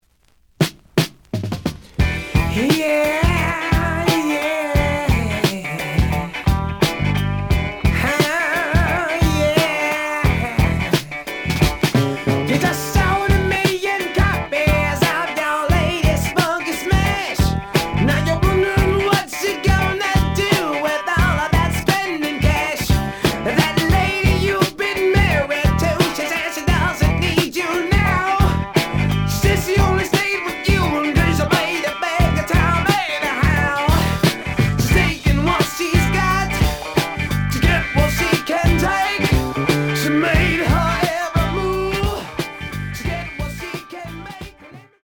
The audio sample is recorded from the actual item.
●Genre: Funk, 70's Funk
Edge warp.